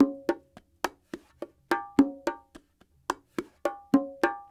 44 Bongo 10.wav